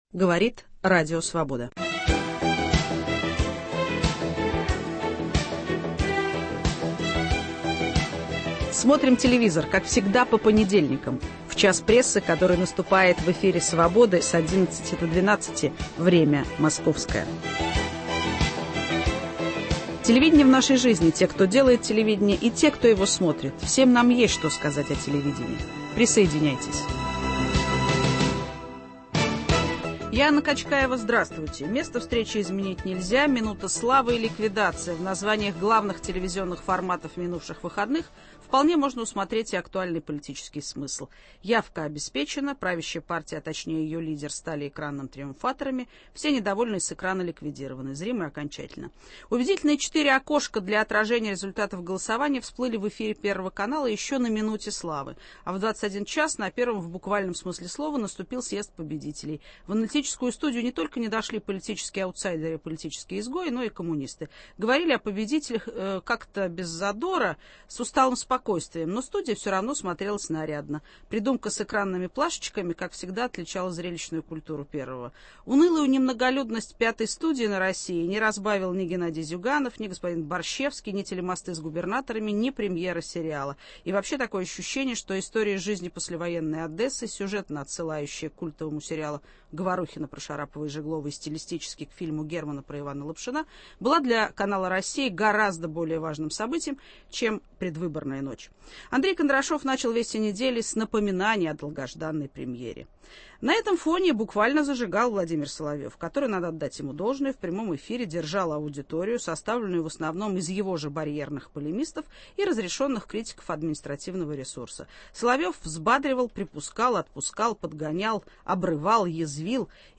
Обсуждение минувших выборов на телевидении.